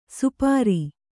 ♪ supāri